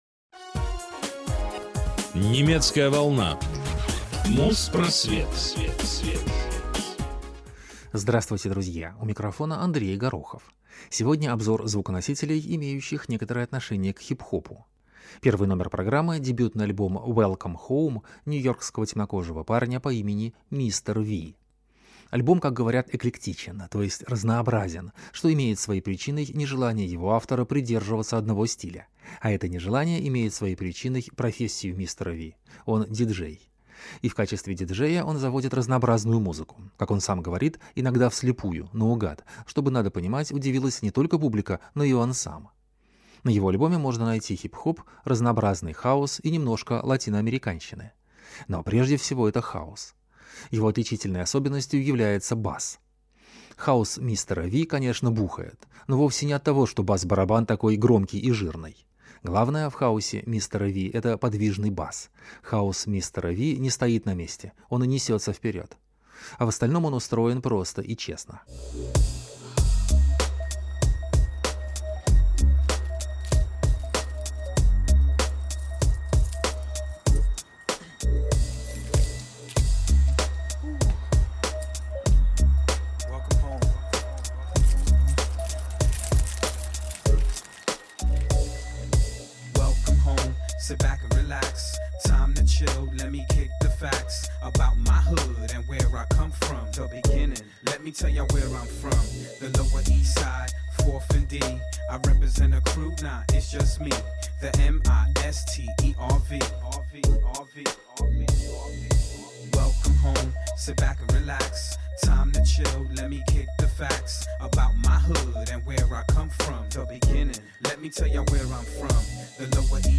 Музпросвет 240 от 17 марта 2007 - Хип-Хоп обзор | Радиоархив